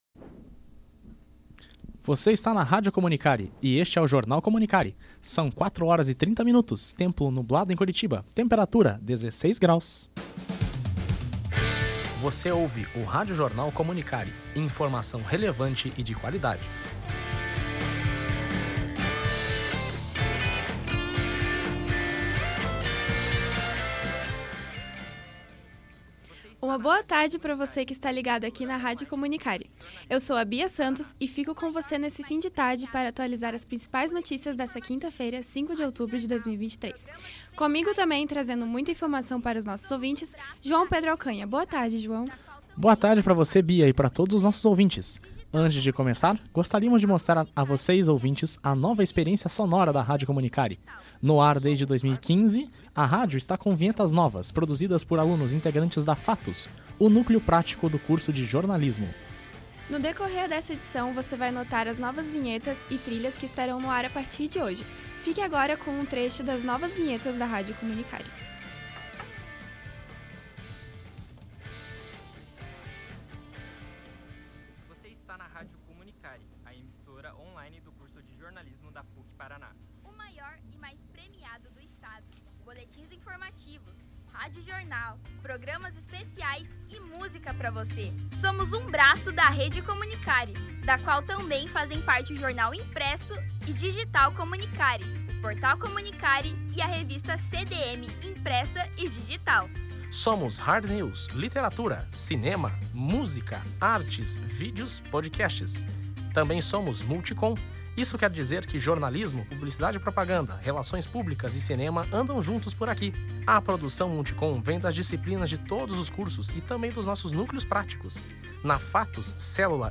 São 26 novas locuções, que abrem e fecham blocos durante as edições de noticiários transmitidos pela rádio.